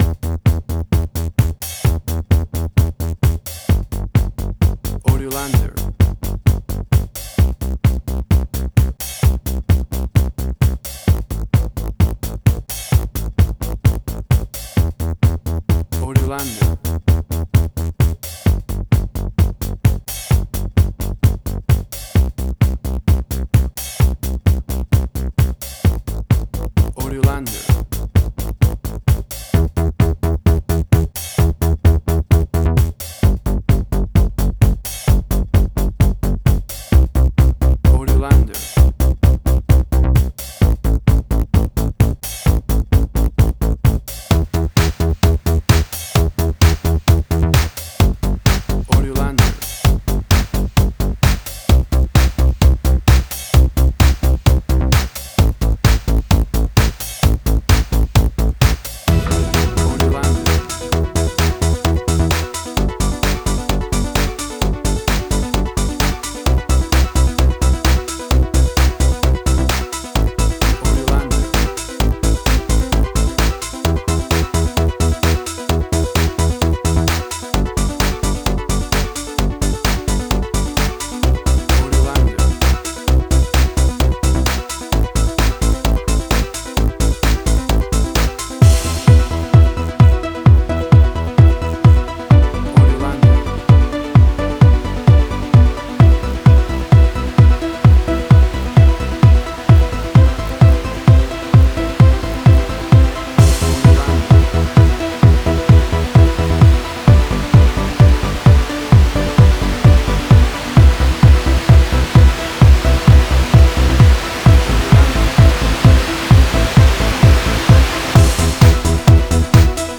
House.
Tempo (BPM): 130